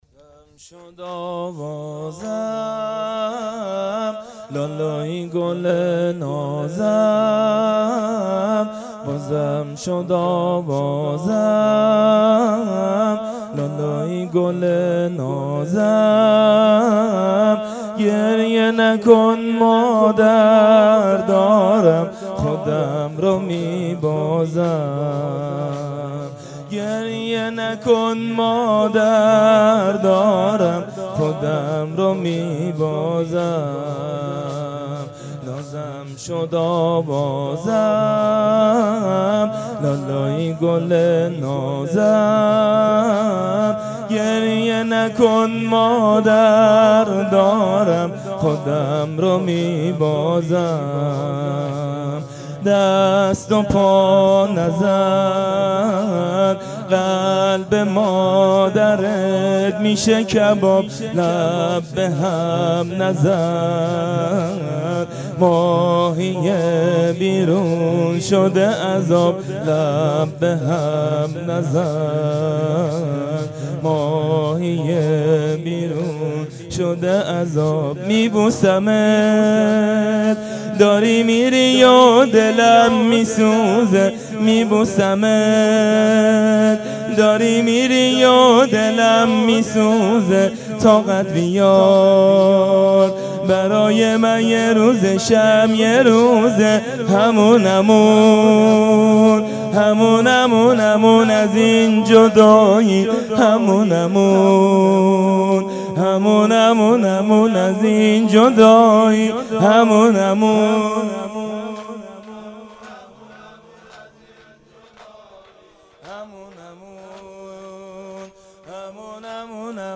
حسینیه بنی فاطمه(س)بیت الشهدا
زمینه شب هفتم محرم 97